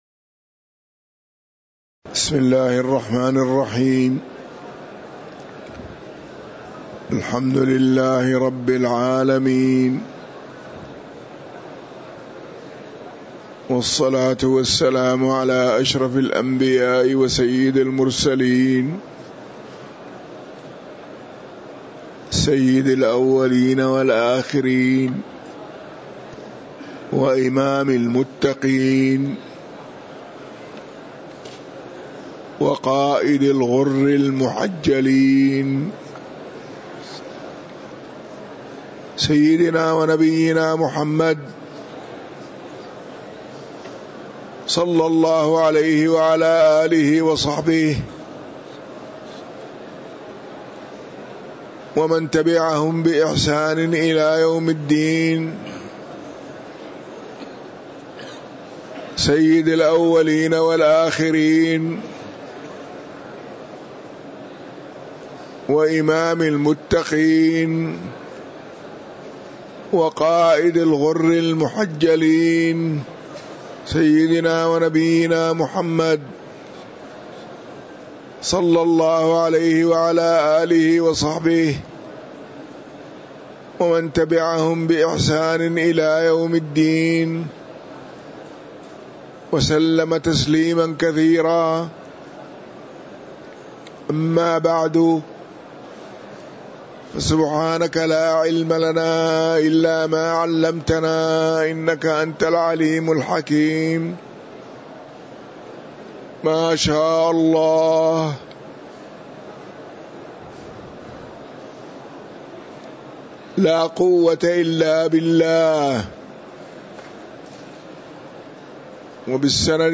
تاريخ النشر ٨ جمادى الأولى ١٤٤٥ هـ المكان: المسجد النبوي الشيخ